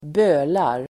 Uttal: [²b'ö:lar]